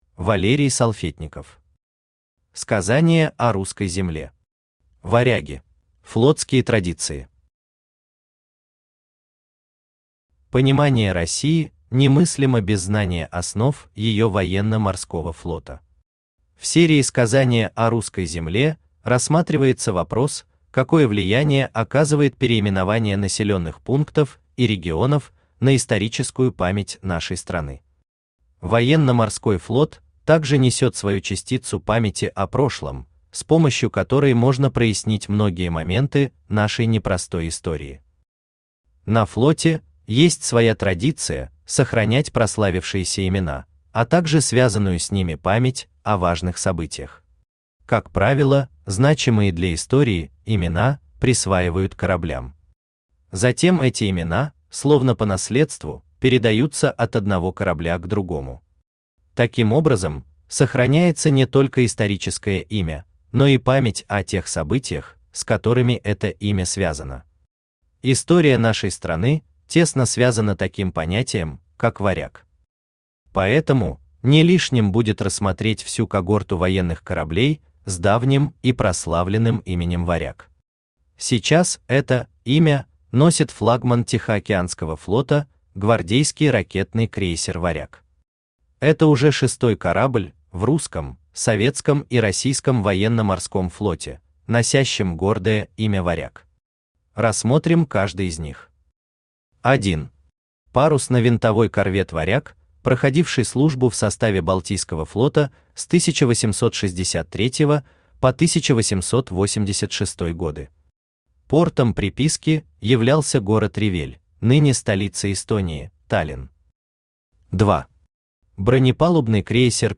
Aудиокнига Сказание о Русской земле. Варяги Автор Валерий Салфетников Читает аудиокнигу Авточтец ЛитРес.